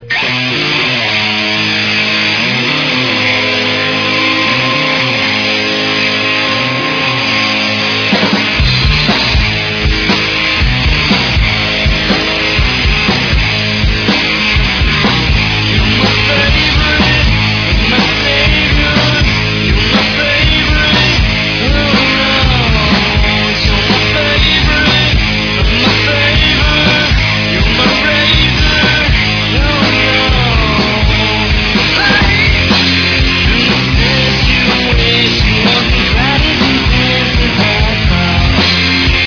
Here are some sound samples from the June/July sessions: